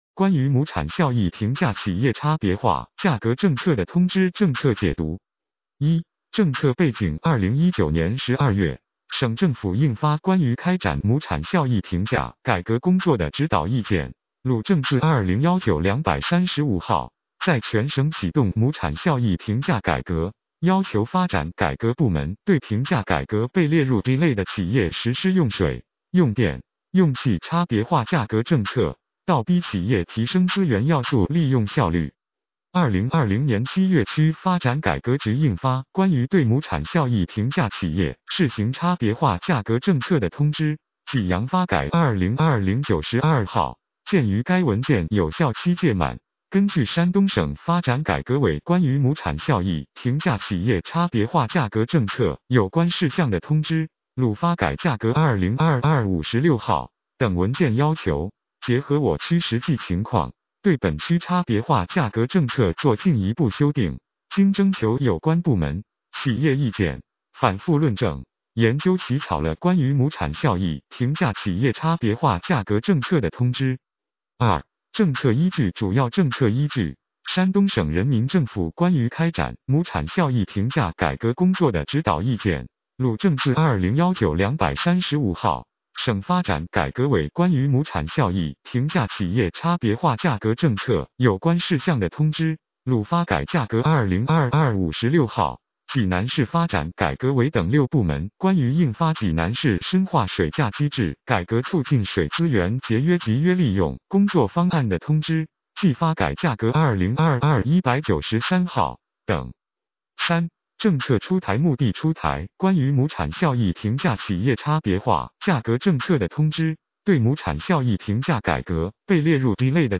有声朗读《关于“亩产效益”评价企业差别化价格政策的通知》